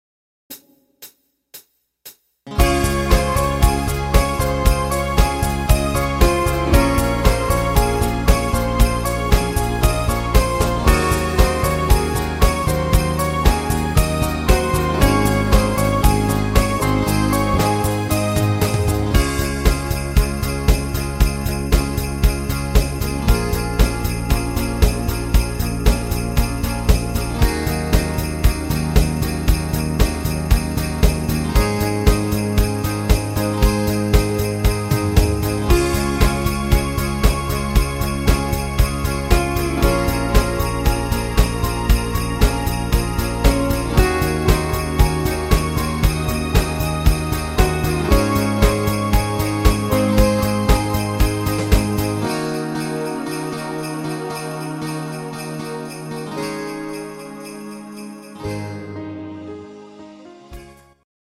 neues Weihnachtslied